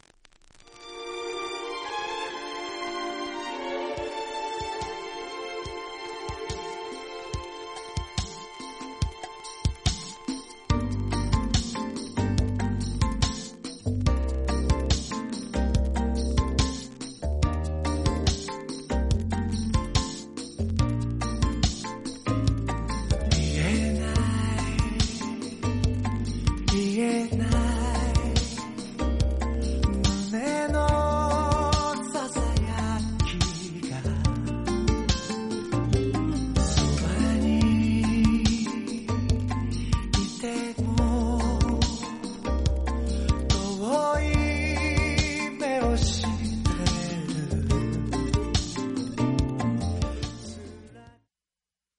J-POPS 搖滾、流行歌曲
実際のレコードからのサンプル↓